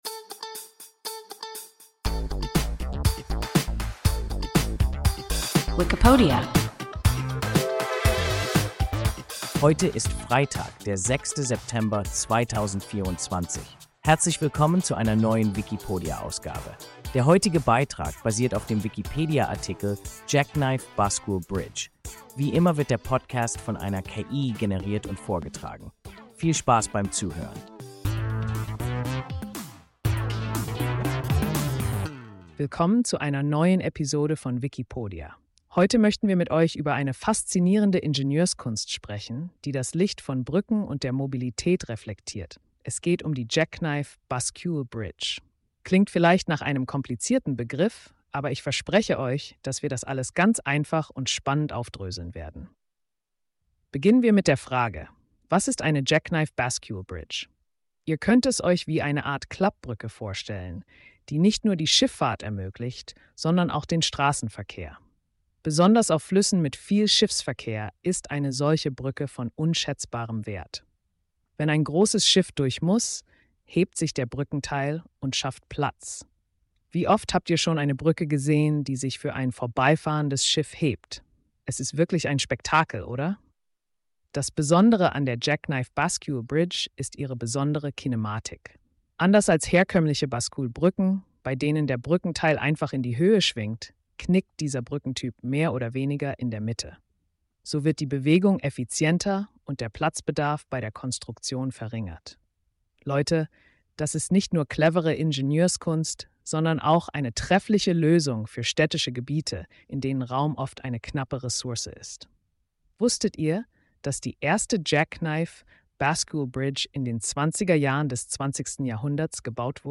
Jackknife Bascule Bridge – WIKIPODIA – ein KI Podcast